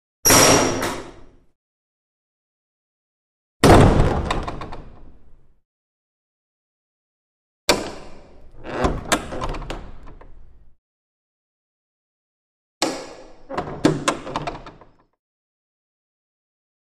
Large Front Door 1; With Spring-bolt, Various Closes, Reverberant Interior